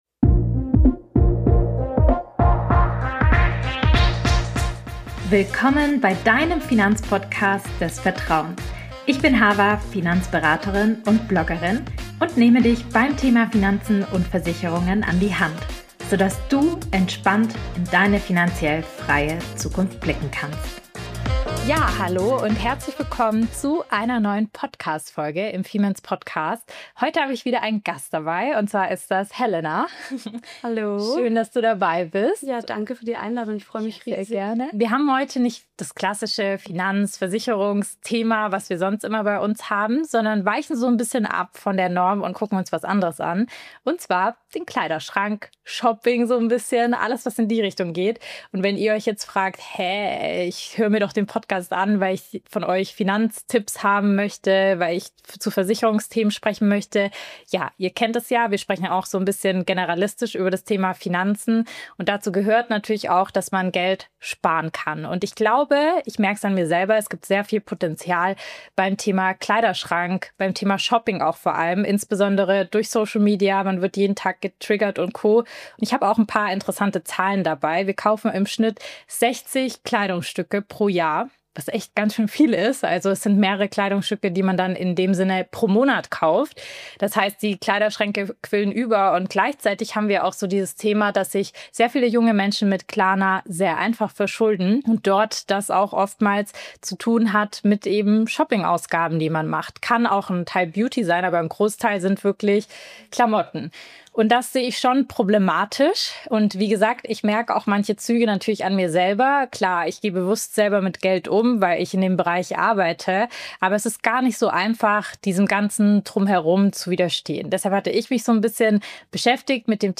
In dieser Podcastfolge spreche ich mit der Personal Stylistin